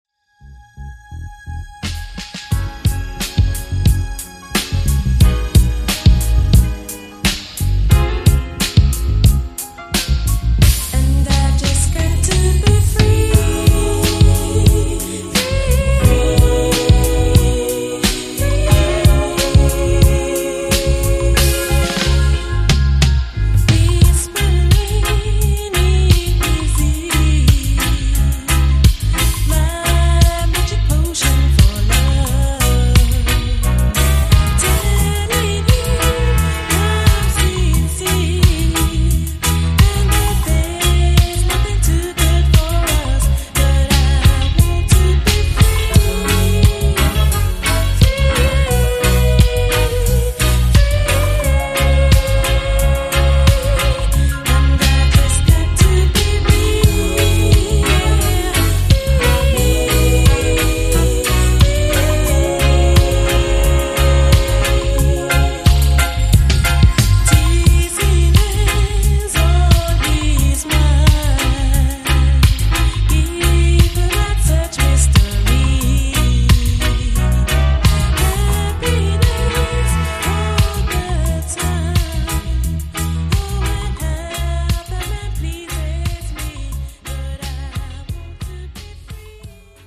Lovers Rock style